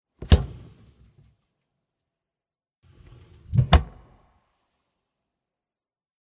Mittlere linke Tür aufschliessen
14021_Mittlere_linke_Tuer_aufschliessen.mp3